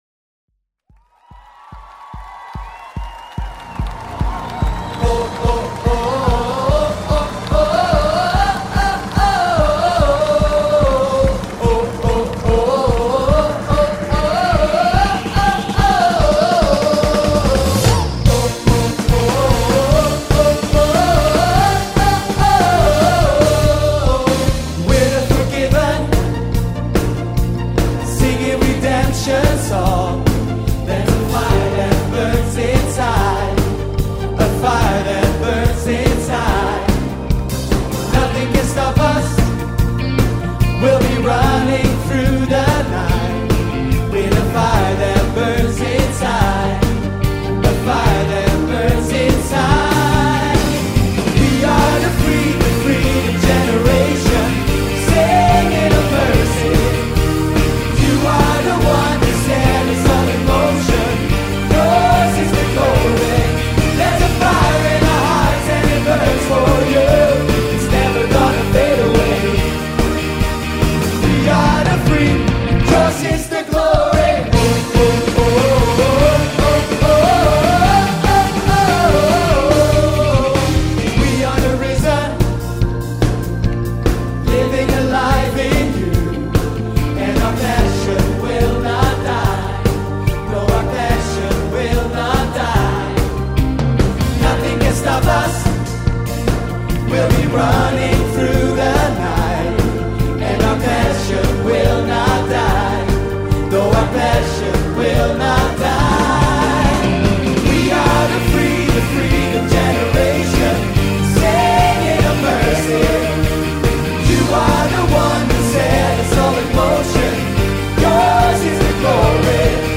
Genre Live Praise & Worship